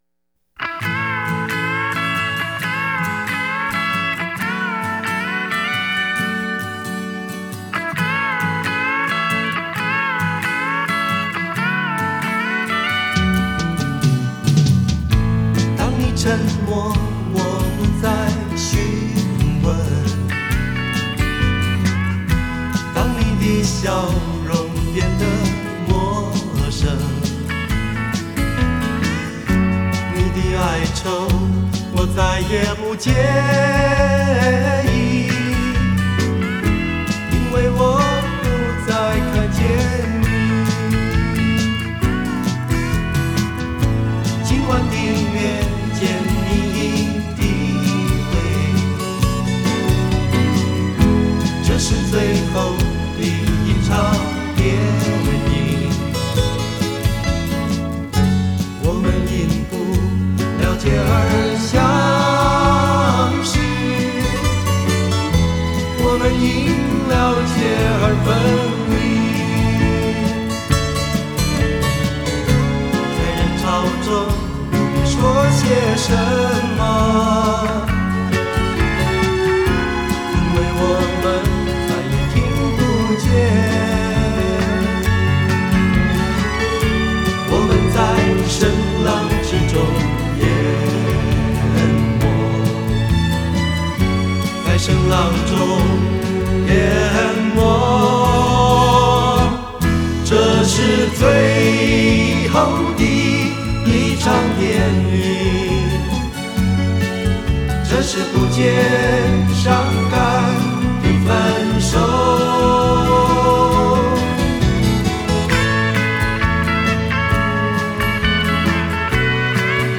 多樣性 的音樂風格，富有變化的和聲呈現，成為當時膾炙人口、津津樂道的校園歌曲。